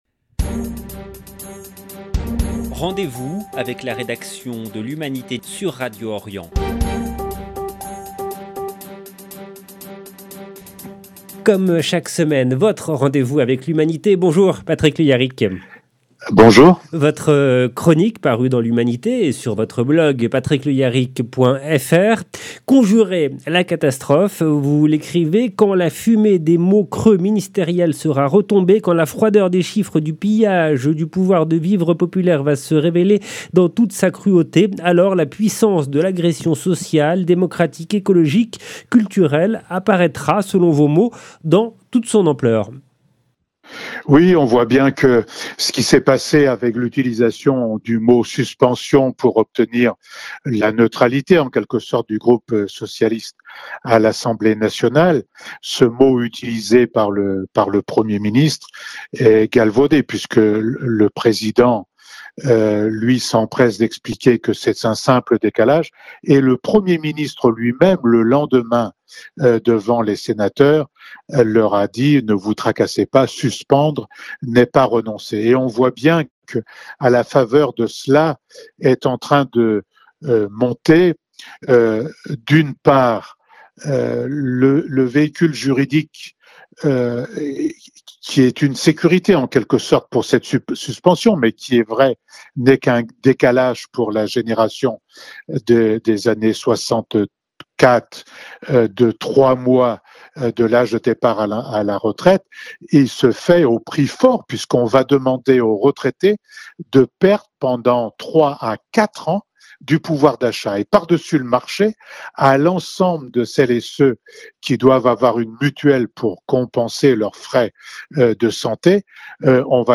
Chronique de l'Humanité du 24/10/2025